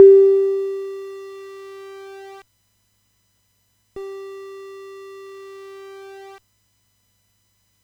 Playing some notes here do not play silence but the same line as normal except (center) already distorted from the start and at a lower volume.
The very last ms ends with quantize effect on top of that.
1// B4 D7 and D6 are set (center)
2// (mute)
B4D7D6_L&R_MUTE.wav